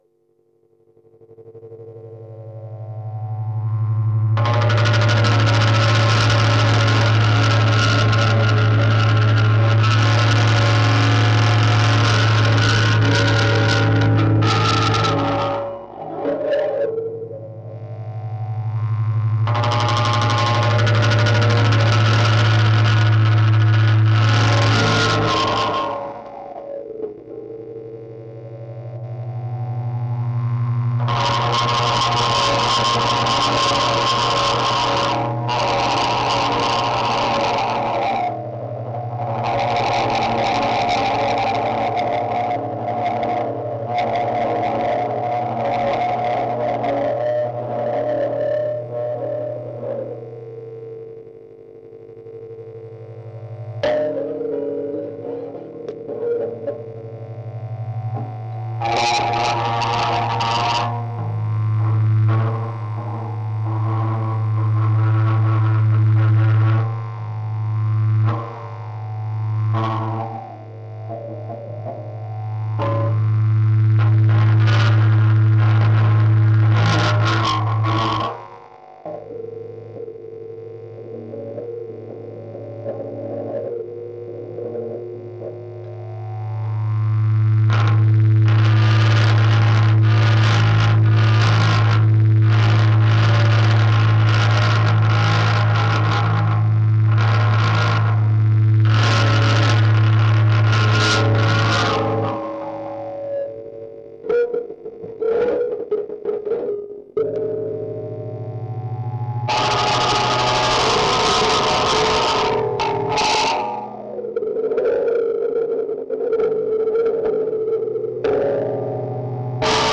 Experimental Music
Music for guitar, e-bow, auto-wah some items and fuzz and Music for guitar, e-bow, auto-wah some items and fuzz 2 added.
0016musicforguitarebowautowahsomeitemsandfuzz.mp3